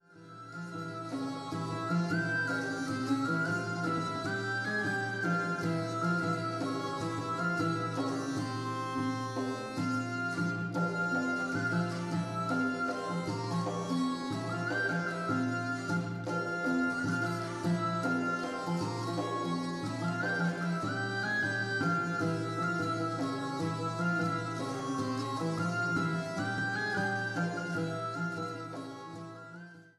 eine bundlose Knickhalslaute
INSTRUMENTAL